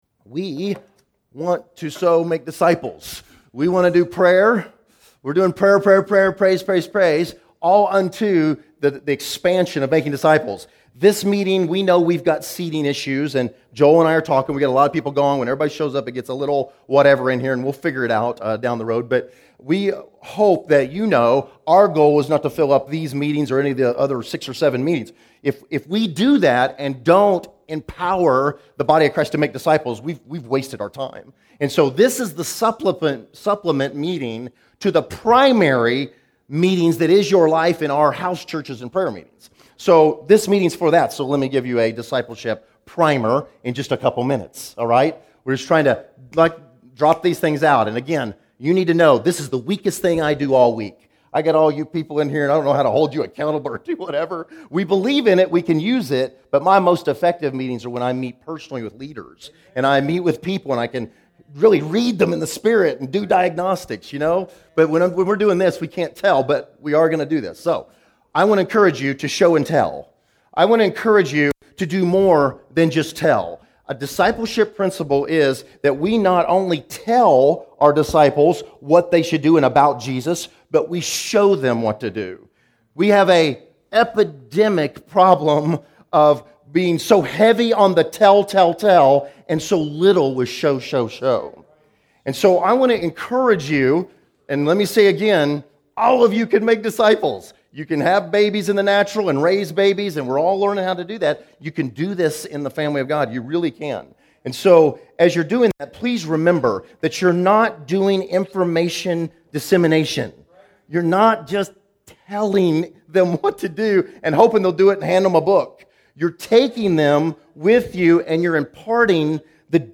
Location: Wichita